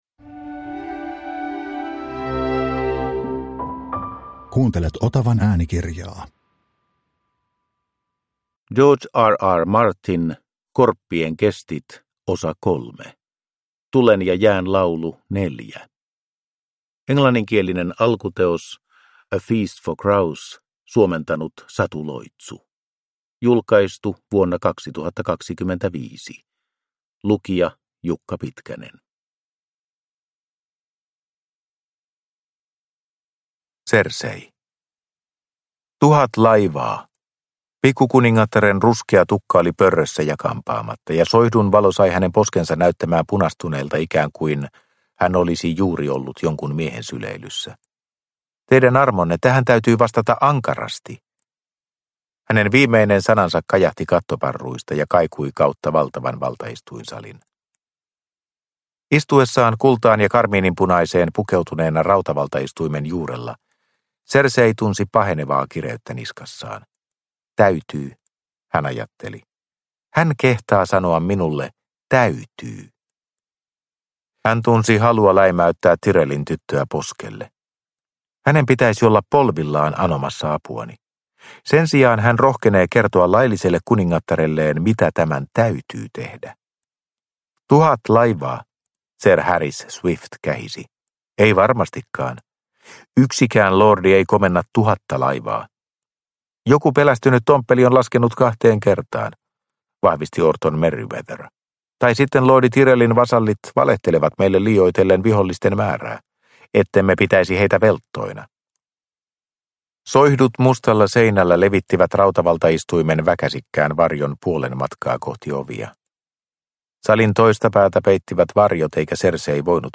Korppien kestit 3 – Ljudbok